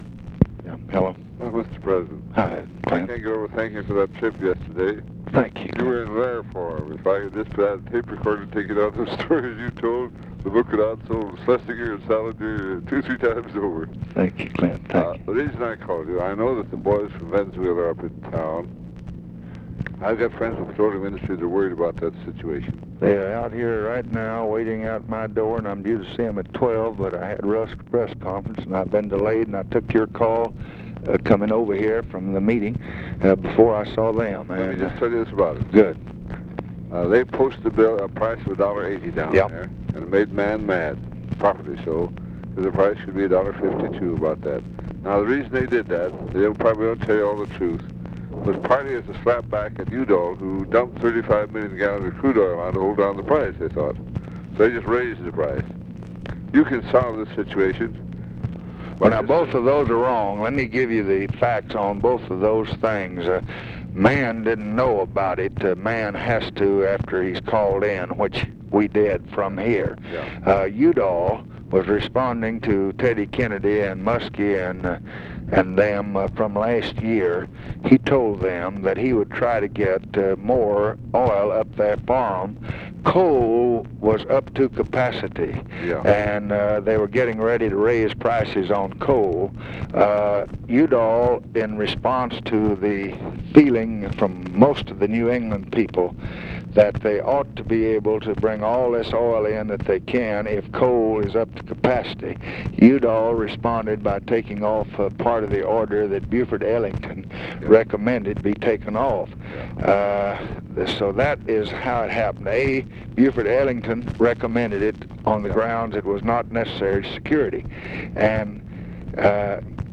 Conversation with CLINTON ANDERSON, January 21, 1966
Secret White House Tapes